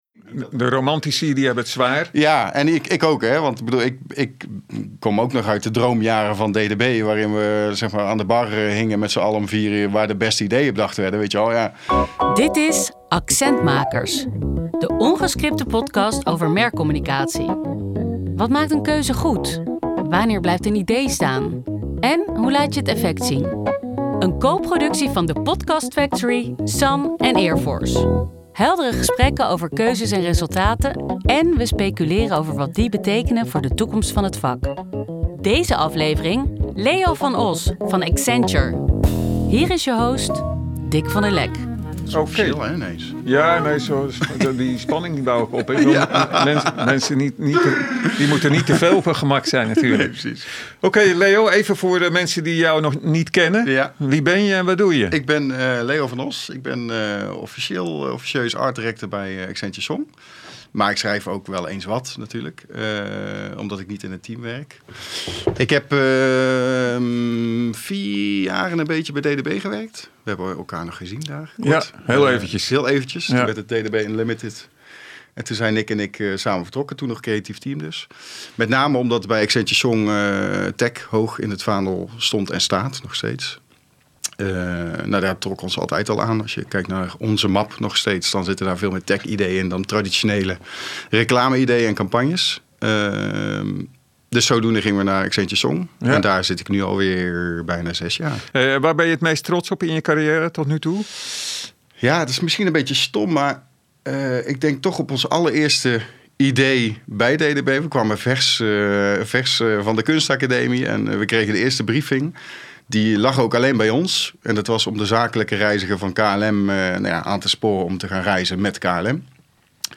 Accent Makers is de ongescripte podcast van SAN, Earforce en Podcast Factory voor iedereen die marketingcommunicatie maakt, beoordeelt of probeert te begrijpen.